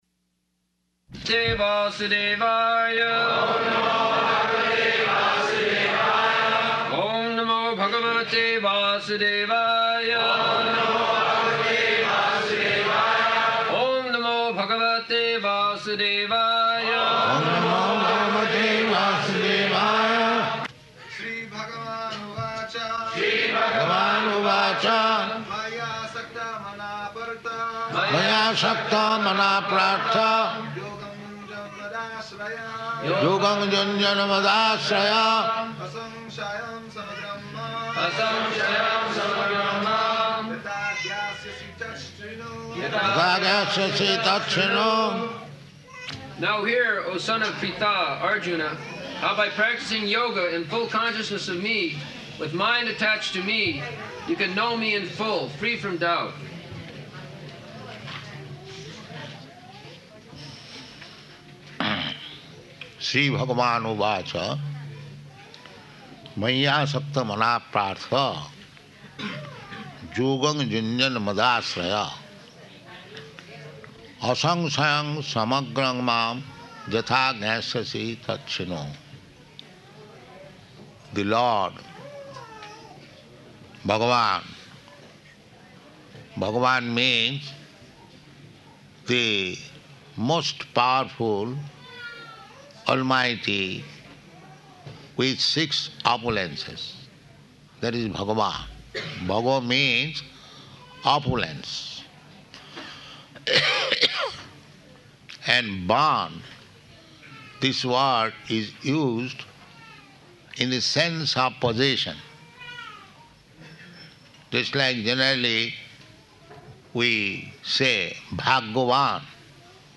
March 9th 1975 Location: London Audio file
[Prabhupāda and devotees repeat] [leads chanting of verse, etc.]